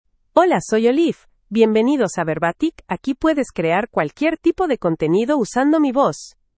FemaleSpanish (United States)
OliveFemale Spanish AI voice
Olive is a female AI voice for Spanish (United States).
Voice sample
Olive delivers clear pronunciation with authentic United States Spanish intonation, making your content sound professionally produced.